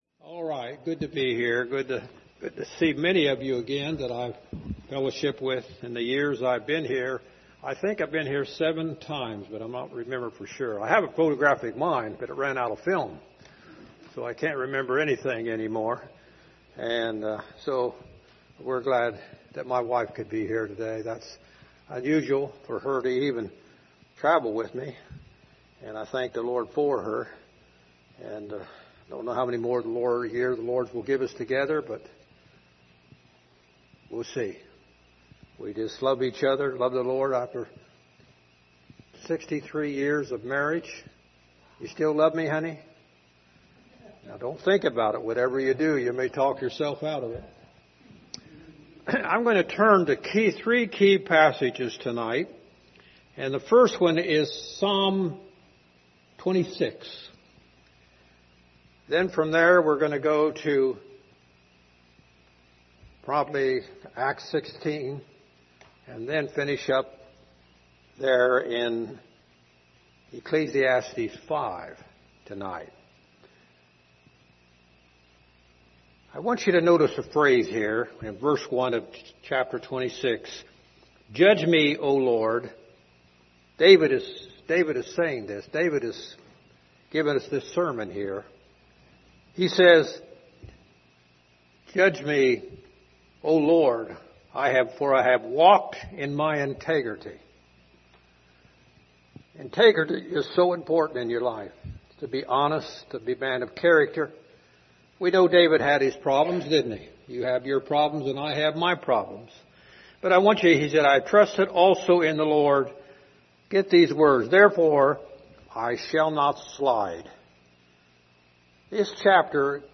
General Passage: Psalm 26 Service Type: Sunday Evening « No Reserve